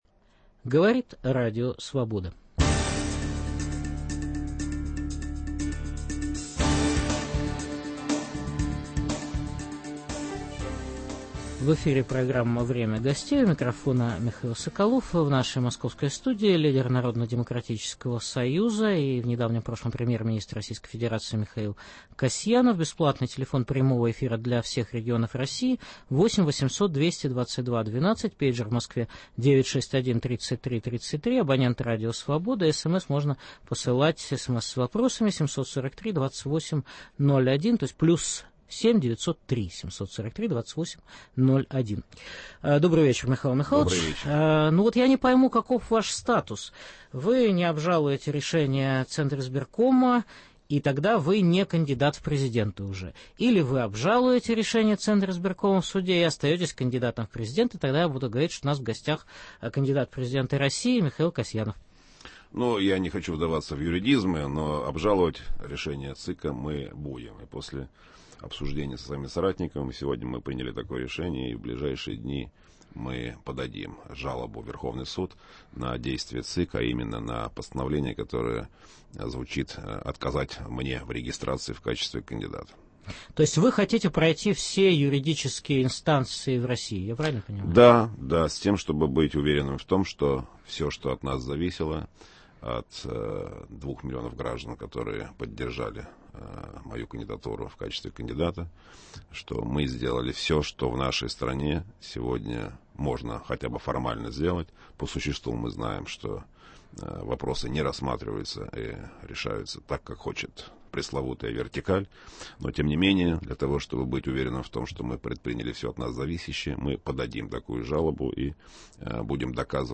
В программе выступит лидер Российского народно-демократического союза Михаил Касьянов.